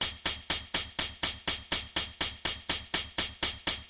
Breakbeat " 120个帽子
Tag: 电子 娱乐 帽子 H IGH 混合 加工